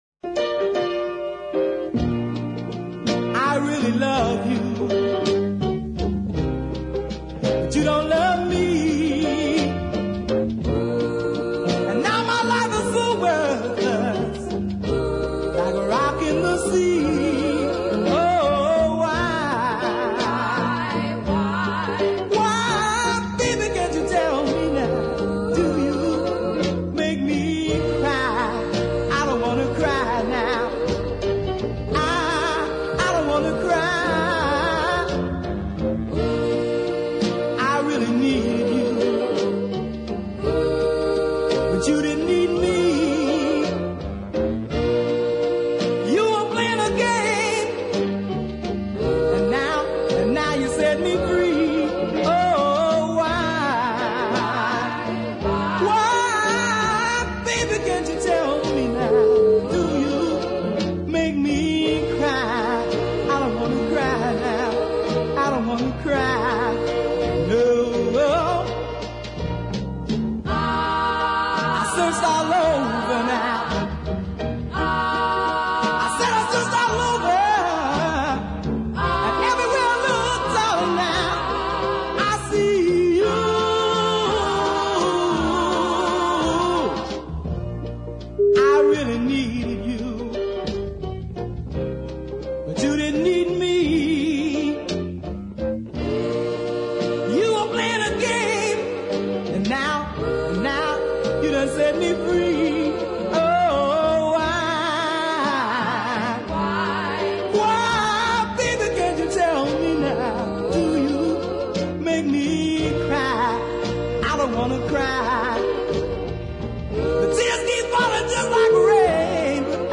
a beautifully organised blues ballad
Essential stuff for anybody who likes heavy R & B.